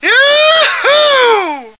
M2yeehaw.ogg